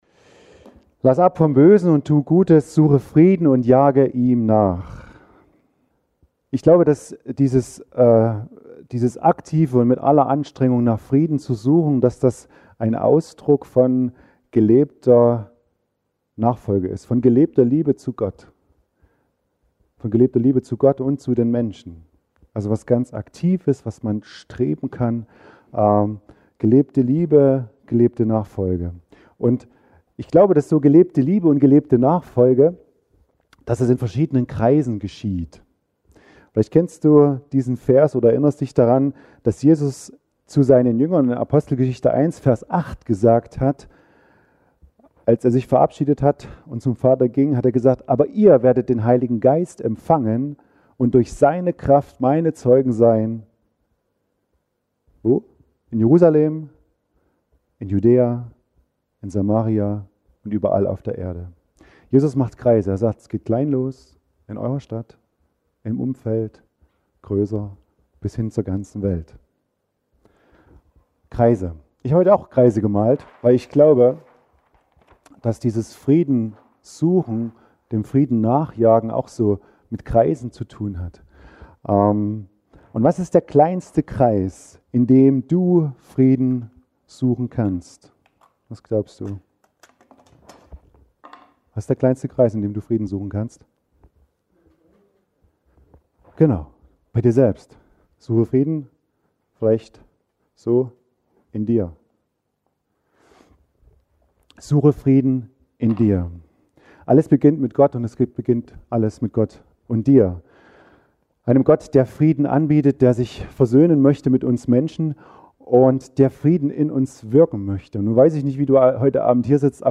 Suche Frieden – Freie evangelische Gemeinde Brandis